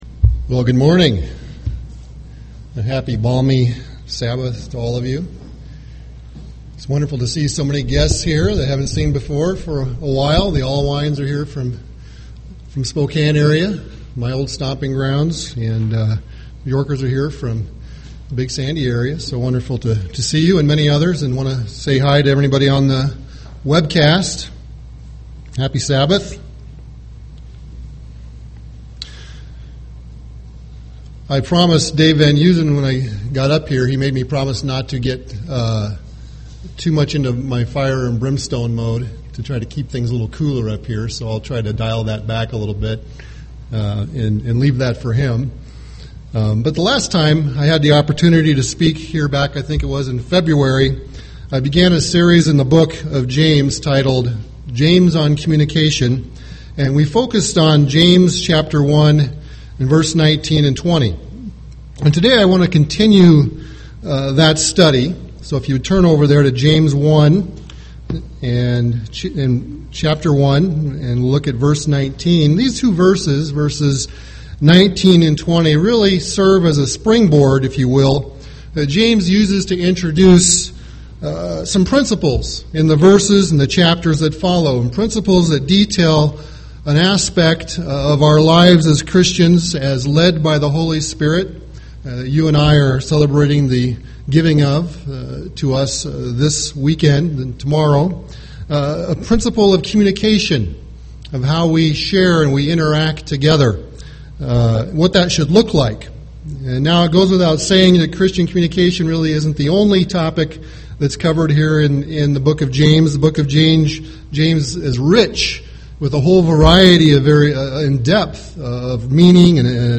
Given in Twin Cities, MN
UCG Sermon tounge Communication James slow to speak Studying the bible?